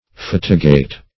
Search Result for " fatigate" : The Collaborative International Dictionary of English v.0.48: Fatigate \Fat"i*gate\, a. [L. fatigatus, p. p. of fatigare.